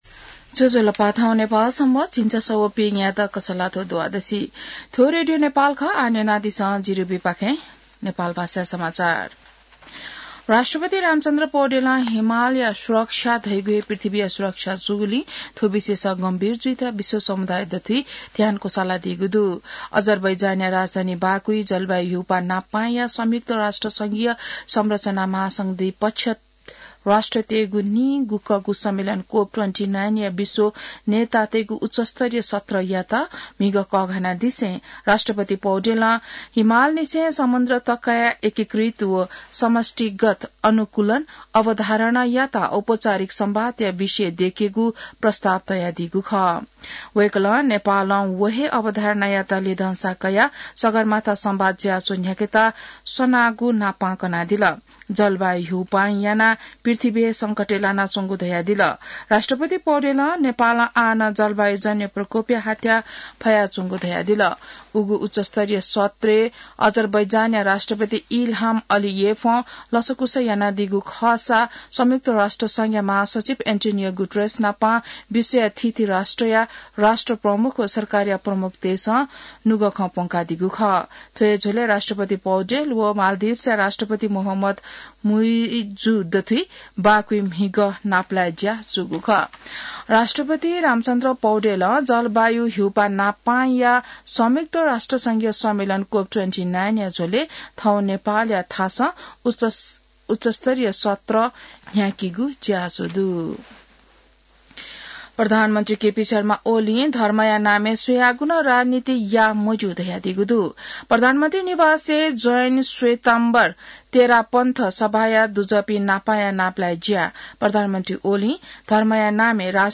नेपाल भाषामा समाचार : २९ कार्तिक , २०८१